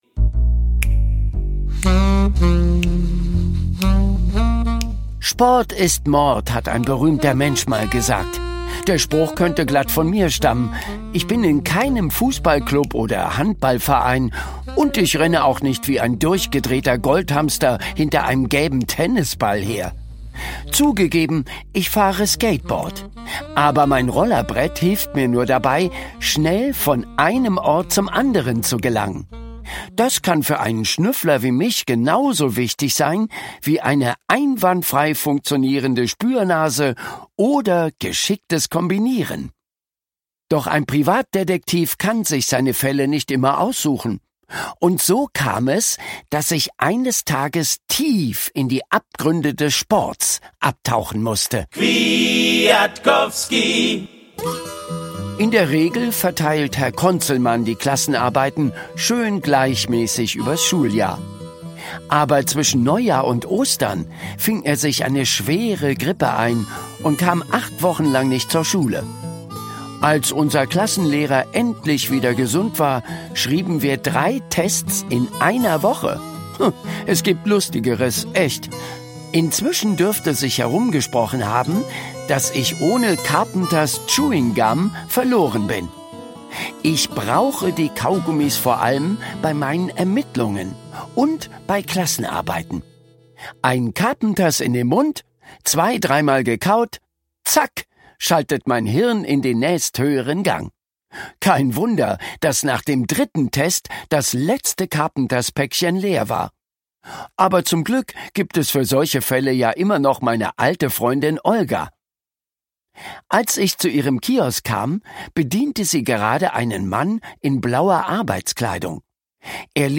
Schlagworte Detektiv • Hörbuch; Lesung für Kinder/Jugendliche • Kinder/Jugendliche: Krimis • Kinderkrimi • Krimis/Thriller; Kinder-/Jugendliteratur • Tennis